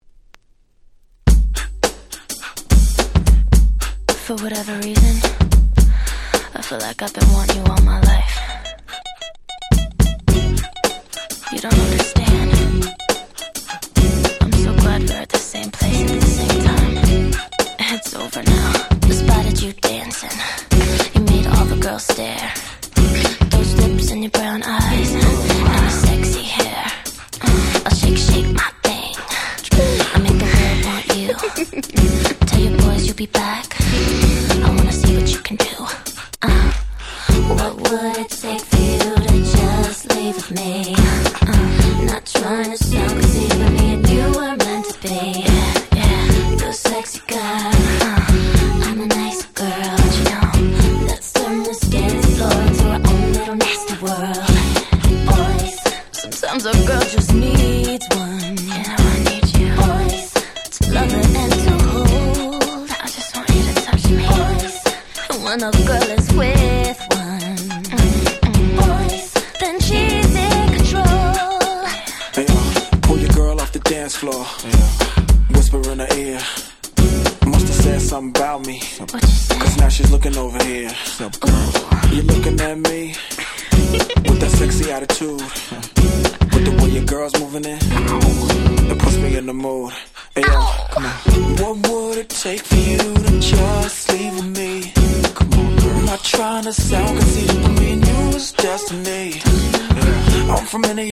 02' Smash Hit R&B !!